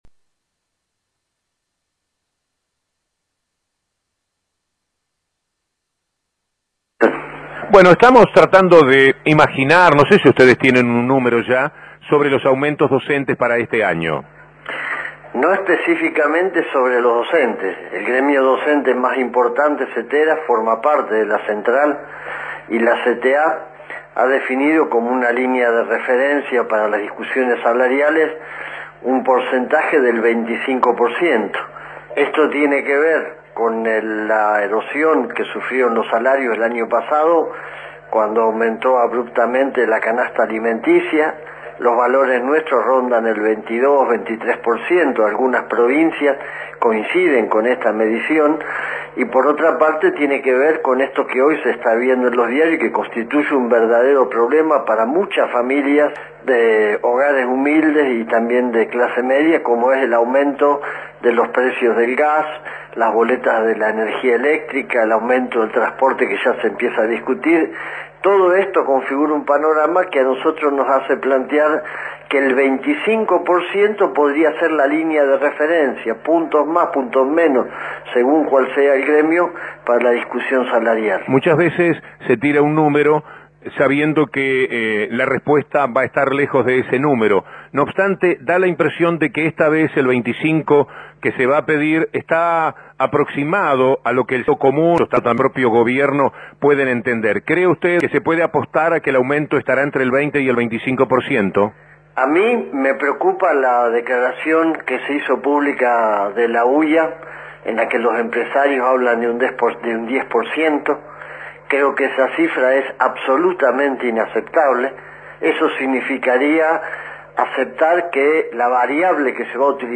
AUDIO ENTREVISTA (MP3 - 1.6 MB)
- 06/02/09 - Entrevista a Hugo Yasky